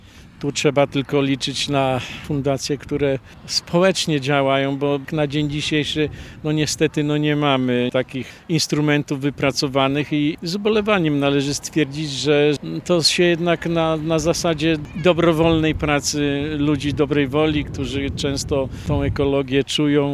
podczas dzisiejszej konferencji prasowej